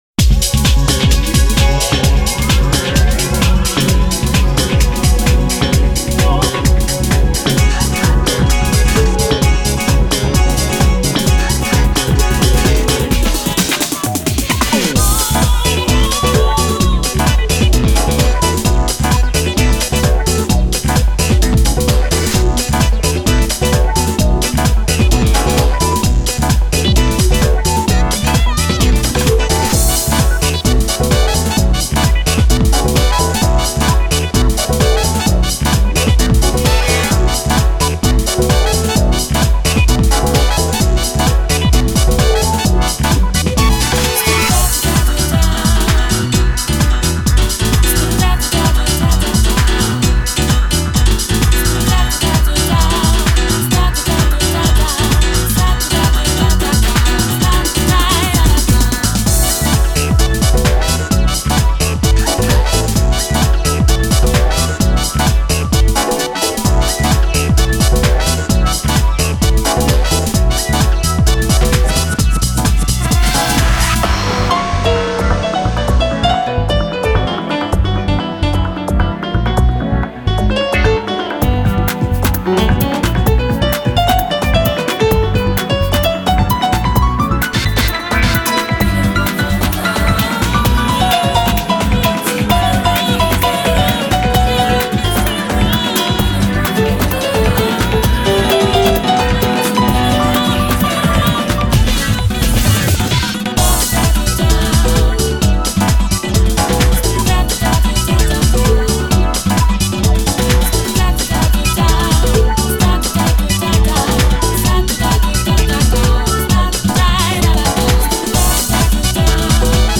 BPM130
Audio QualityPerfect (High Quality)
The rhythm will have you swinging like your legs are broken!